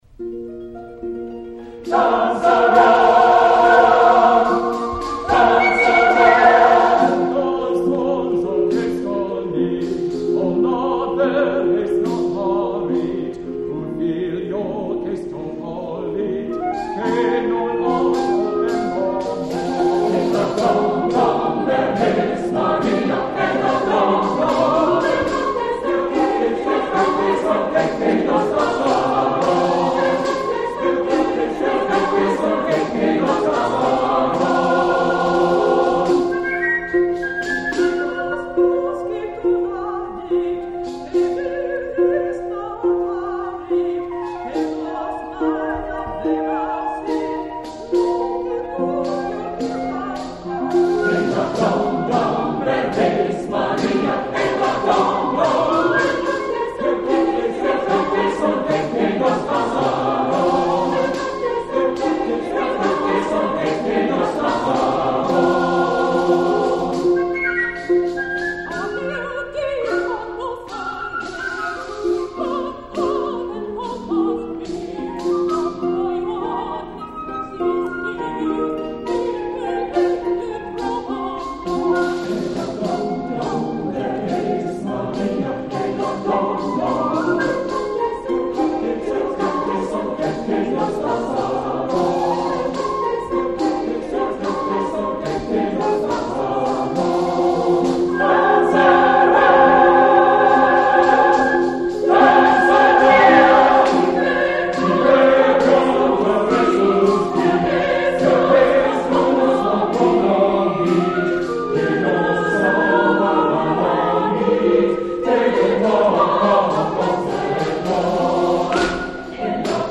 Voicing: SATB,Harp,